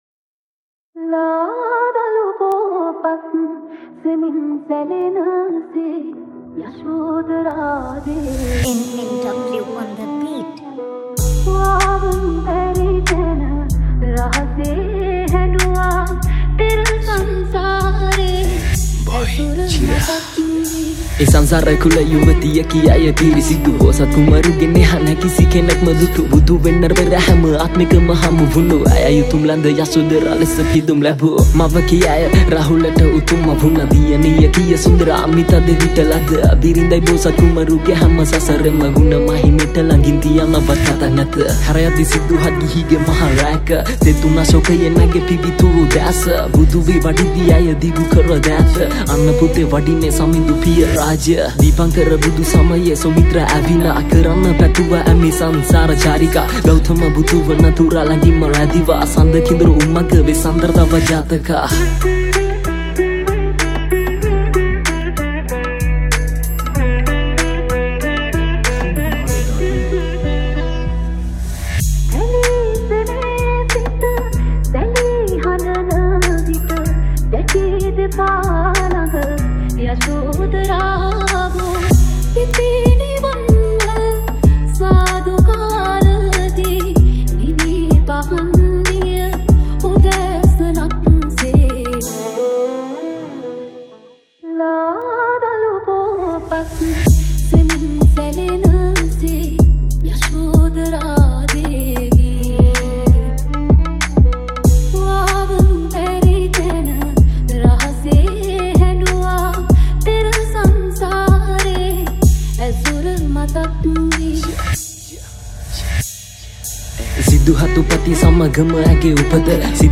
Category: Rap Songs